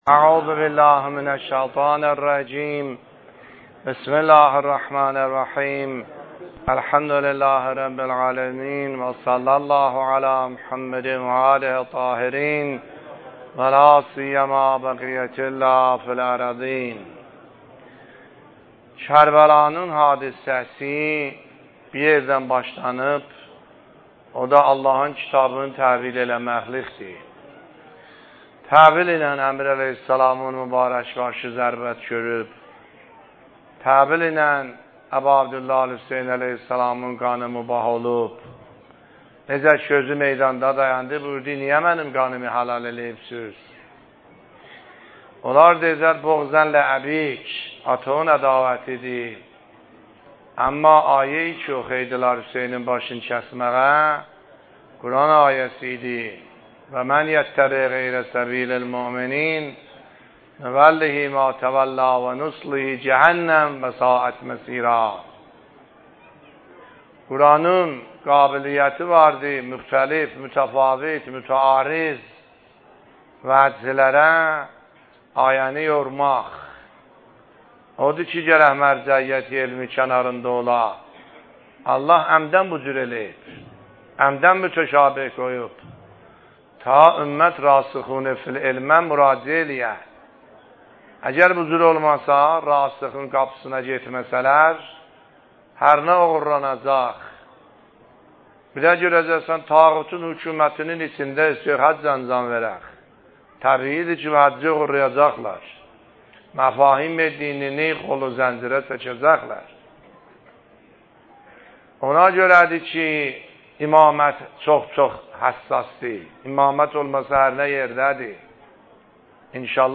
سخنرانی آیه الله سیدحسن عاملی فایل شماره ۹- دهه اول محرم ۱۳۹۷